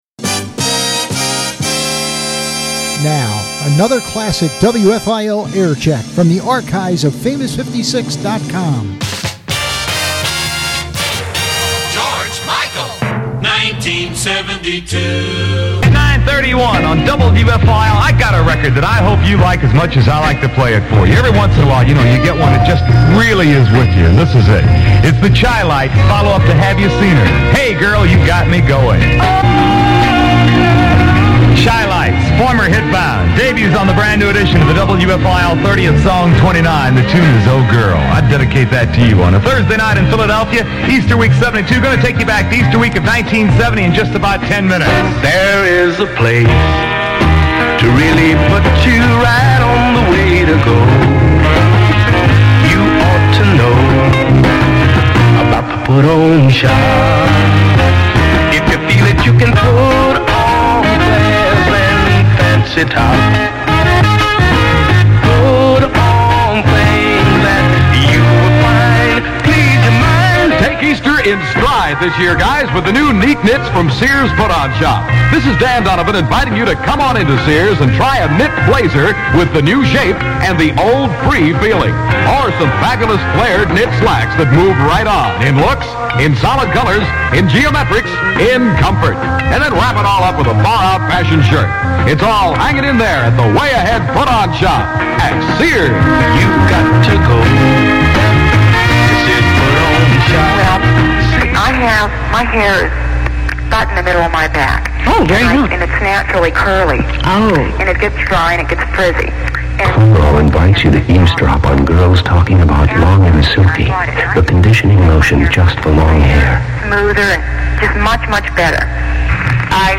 This Week's Aircheck